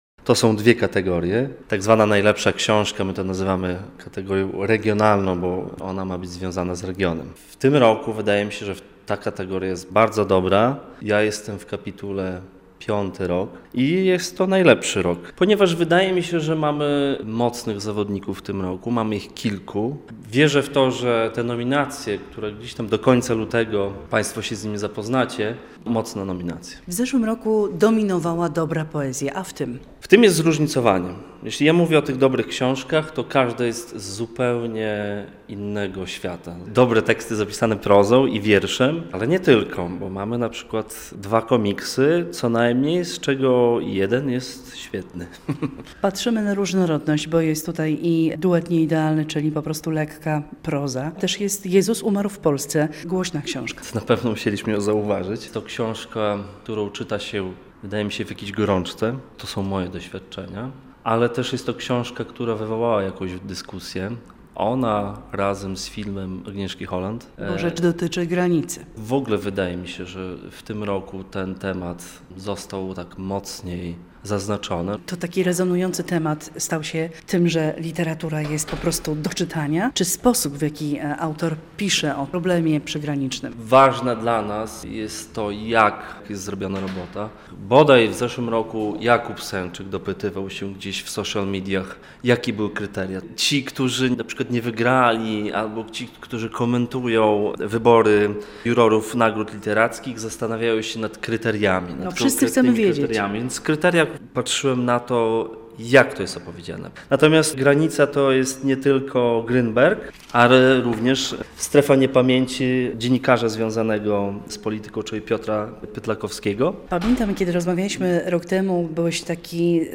rozmawiamy o literaturze regionalnej z jednym z jurorów